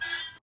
pulley_short.ogg